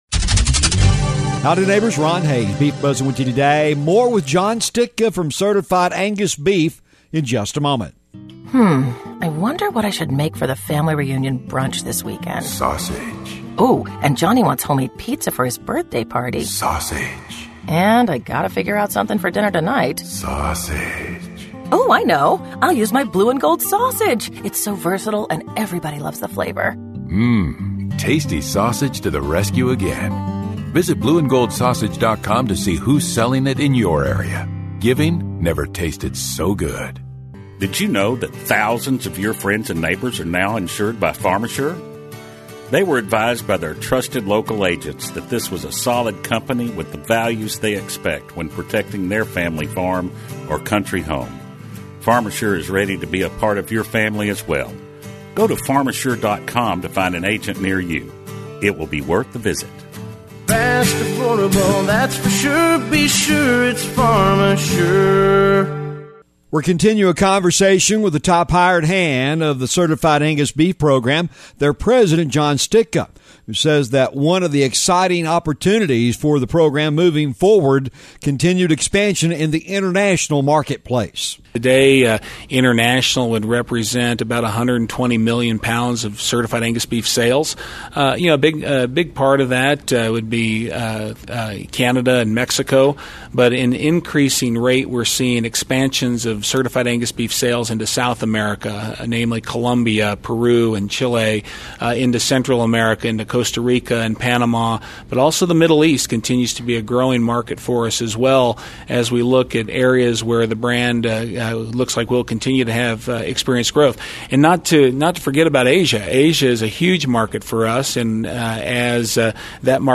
The Beef Buzz is a regular feature heard on radio stations around the region on the Radio Oklahoma Network- but is also a regular audio feature found on this website as well.